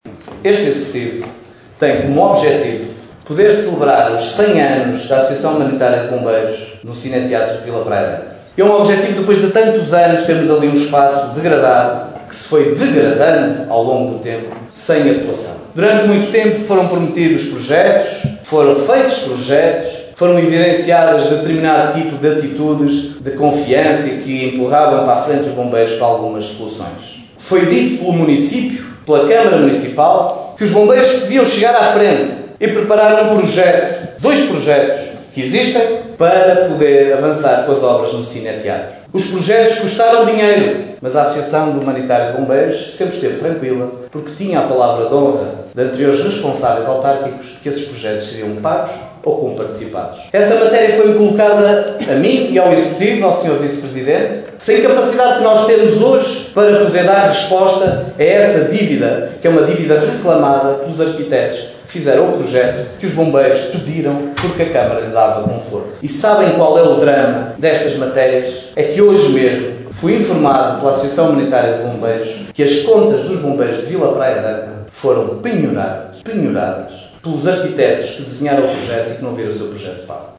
O problema foi abordado pelo presidente da Câmara na última Assembleia Municipal de Caminha, realizada durante a noite e madrugada de Sexta-feira.
assembleia-municipal-bombeiros-vpa-miguel-alves-1.mp3